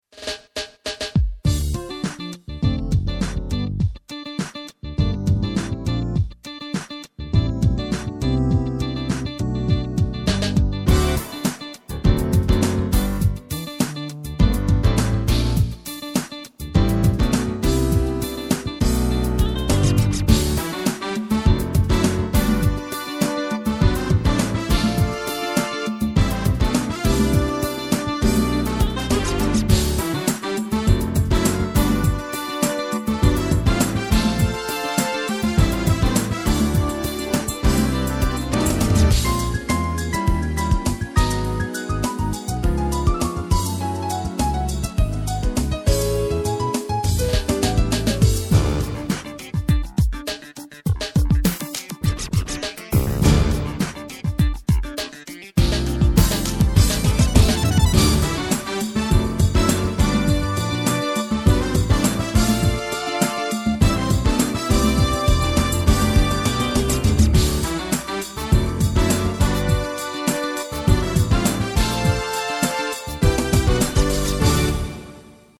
Soundbeispiele Yamaha PSR E 413